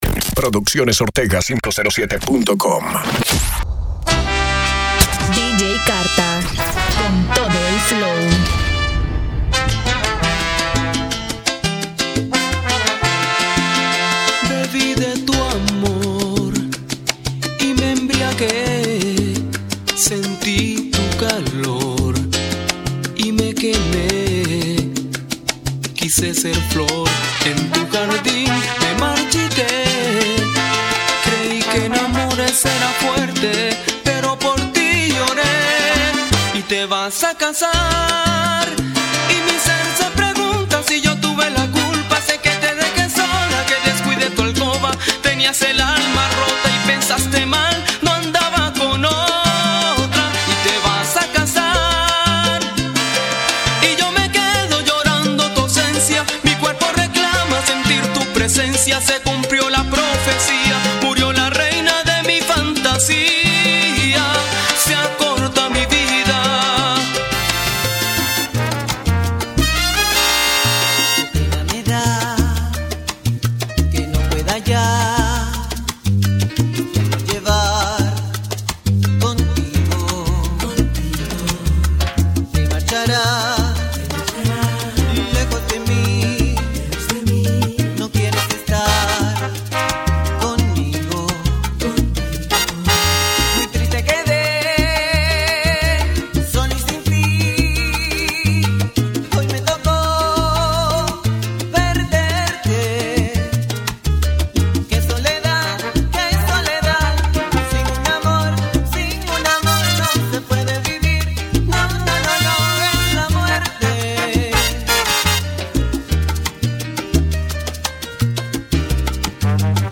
Mixes, Salsa